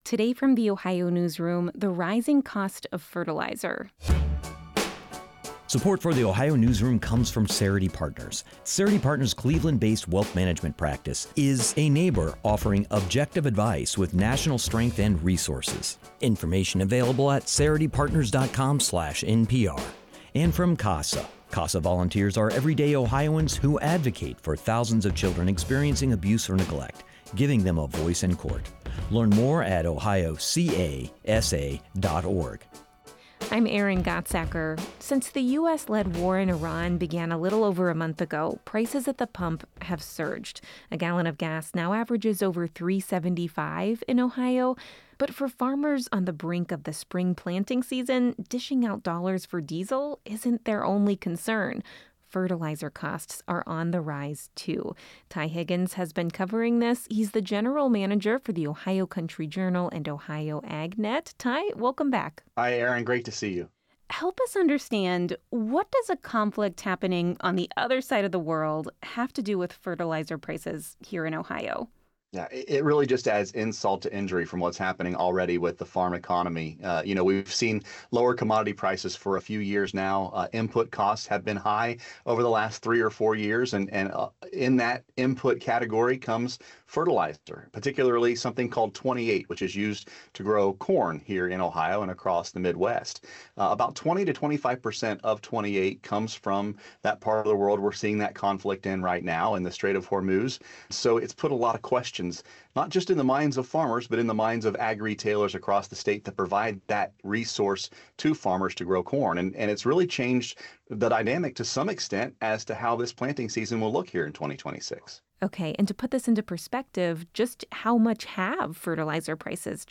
This interview has been lightly edited for clarity and brevity.